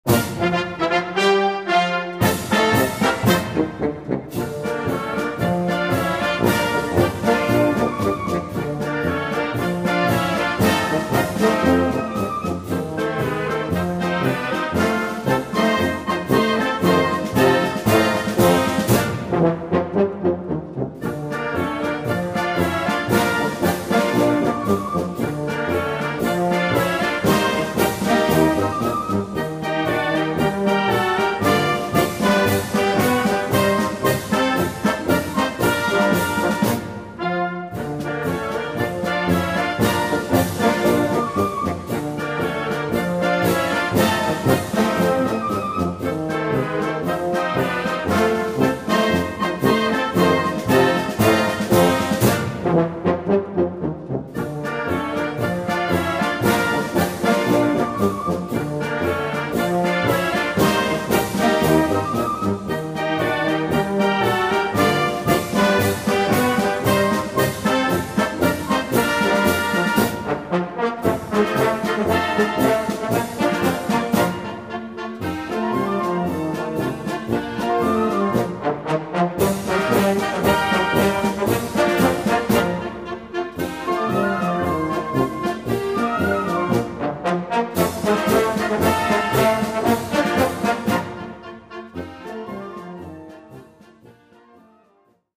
Flûte Traversière